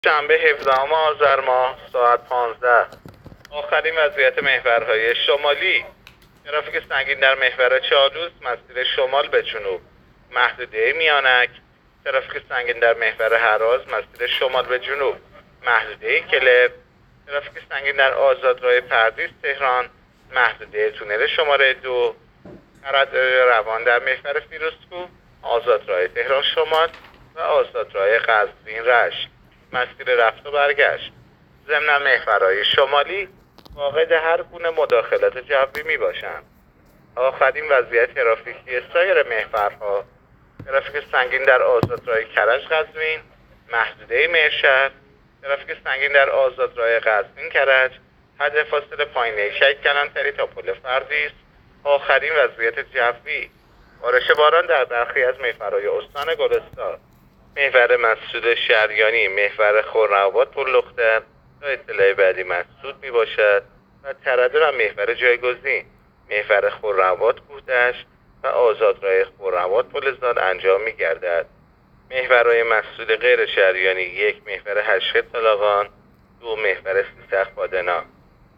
گزارش رادیو اینترنتی از آخرین وضعیت ترافیکی جاده‌ها تا ساعت ۱۵ هفدهم آذر؛